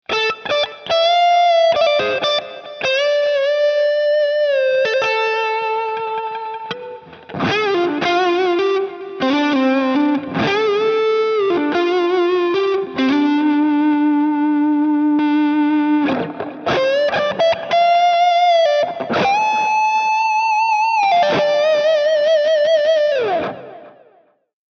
Based on a Marshall Amp Stack.
All tones were recorded via USB straight into Logic X.
No post FX were added.
3. Smooth Cream Lead – CTL Activates Boost
SOUND SAMPLES: (CTL Effects are engaged during the sound demo)
03_Smooth-Cream-Lead.mp3